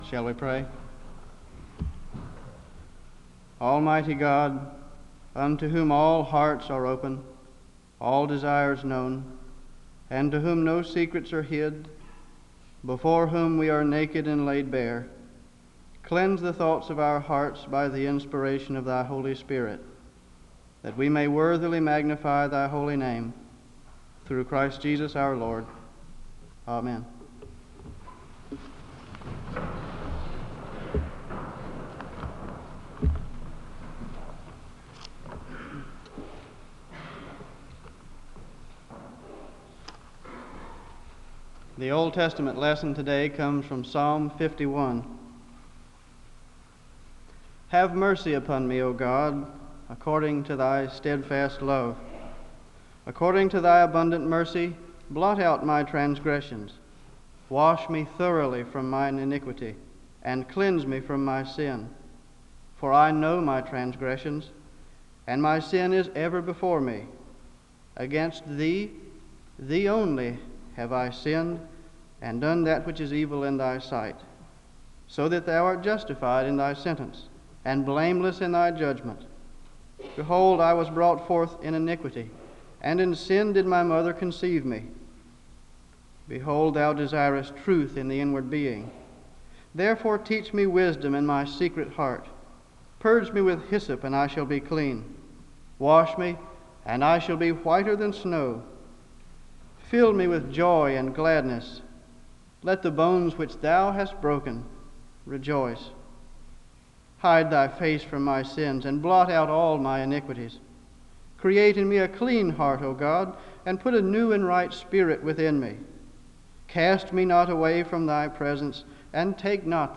The service opens with a word of prayer (00:00-00:27).
The choir sings a song of worship (04:51-07:36).
The choir ends the service with a song of worship (26:59-27:34).